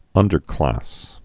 (ŭndər-klăs)